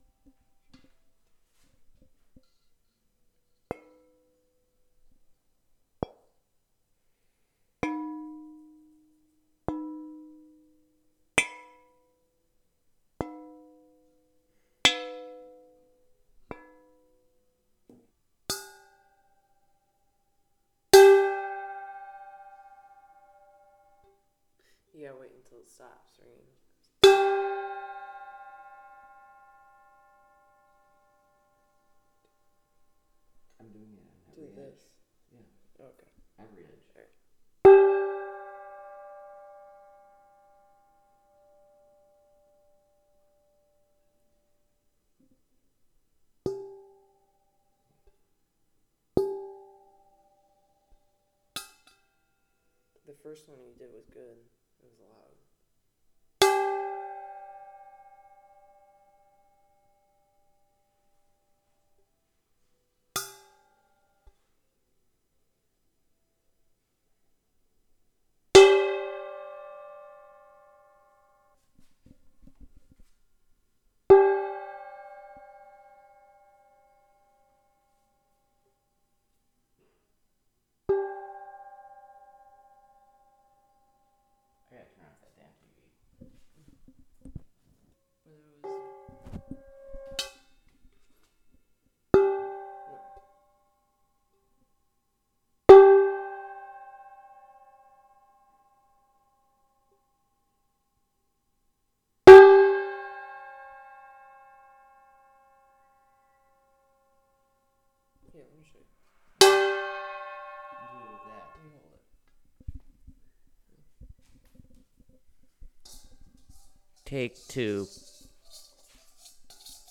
metal_bowl
Bell Boing Bowl Ding Dong Donk Hit Metal sound effect free sound royalty free Sound Effects